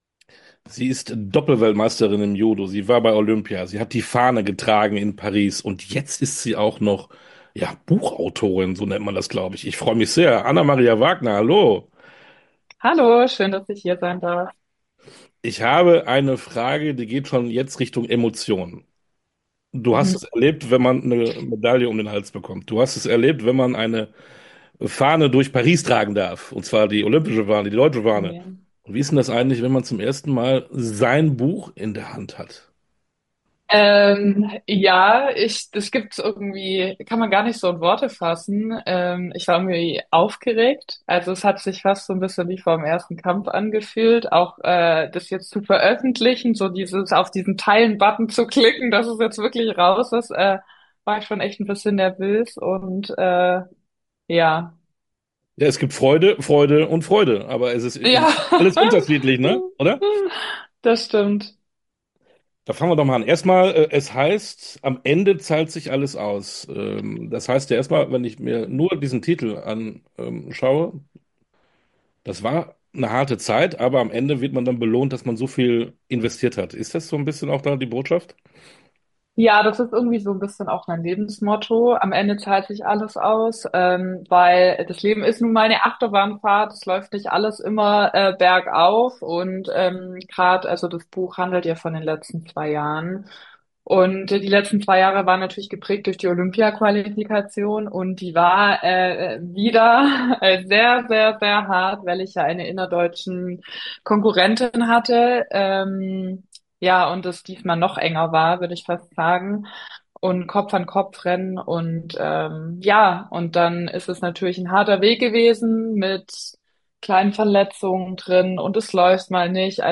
Sportstunde - Interview komplett Anna Maria Wagner, Judo Weltmeisterin und Olympia Fahnenträgerin ~ Sportstunde - Interviews in voller Länge Podcast
Interview_komplett_Anna-Maria_Wagner-_Judo_-_Weltmeisterin_und_Olympia-Fahnentraegerin.mp3